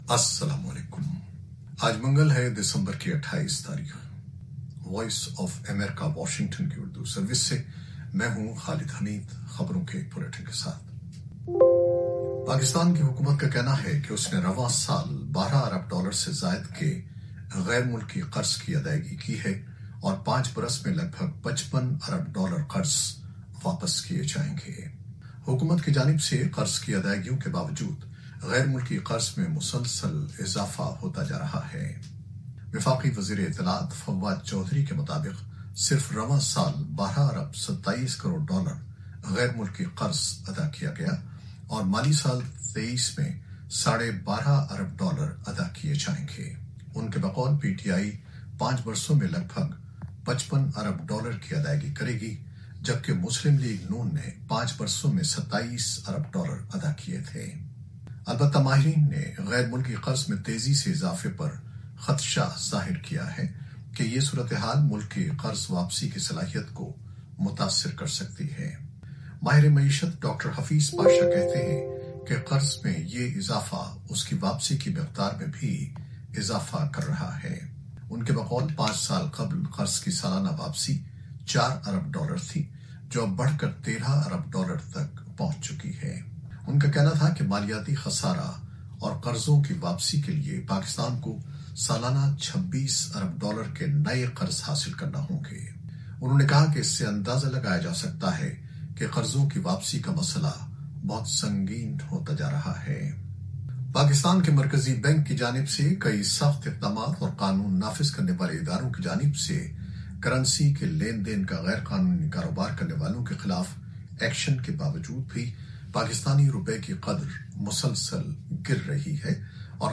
نیوز بلیٹن 2021-28-12
شام سات بجے کی خبریں خالد حمید کے ساتھ۔